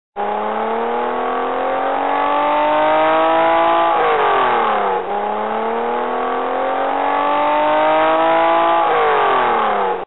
SoundEffect
littleTrolleyDrive.mp3